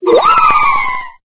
Cri de Phyllali dans Pokémon Épée et Bouclier.